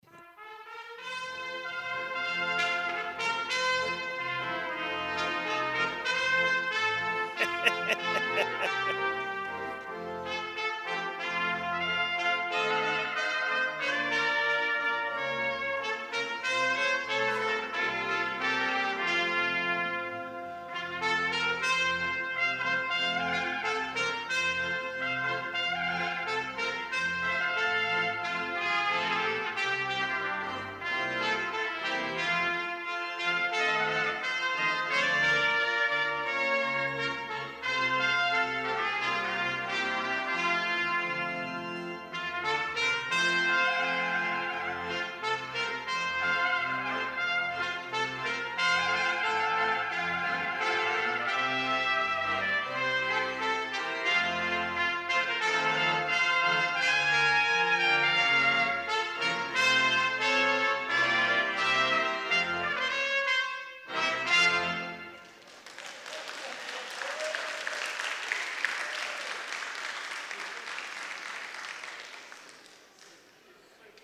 trumpet duet
organ